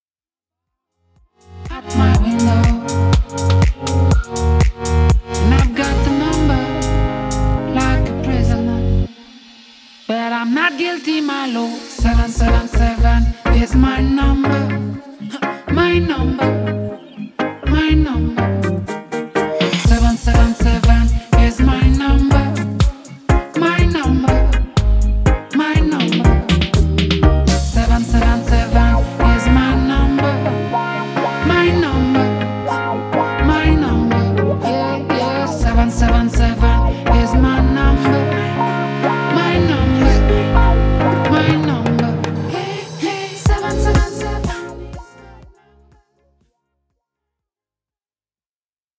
Le reggae reste néanmoins le fil conducteur de cet EP.